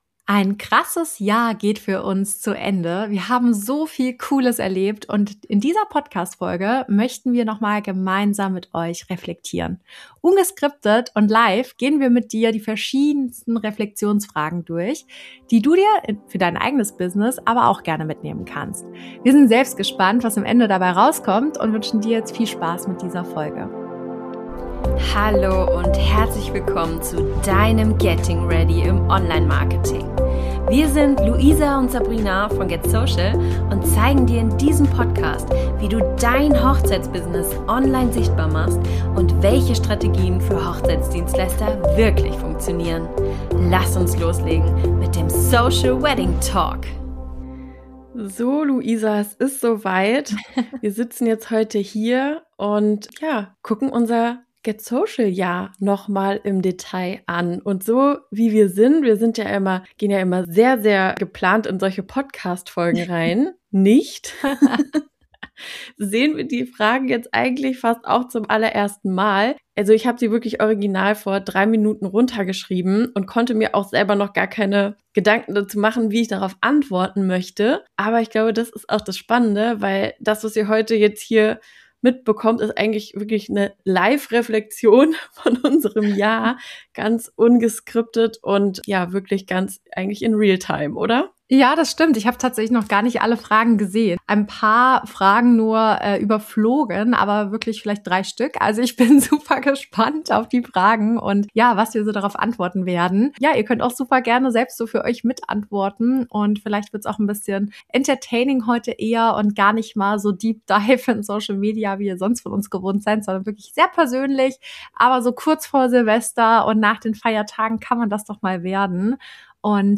Ein intensives, ehrliches und völlig ungeskriptetes Jahresrecap: In dieser Folge nehmen wir euch live mit hinter die Kulissen unseres Jahres 2025 – mit allen Höhen, Learnings, Fails und Aha-Momenten.